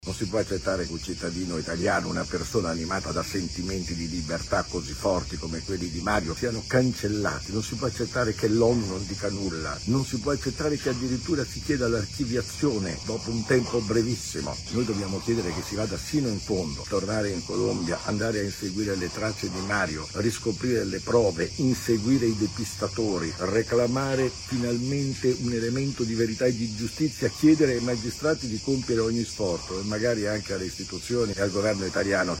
Ascoltiamo Beppe Giulietti, coordinatore nazionale di Articolo 21.